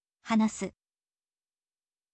hanasu